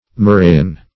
Search Result for " murrayin" : The Collaborative International Dictionary of English v.0.48: Murrayin \Mur"ray*in\, n. (Chem.)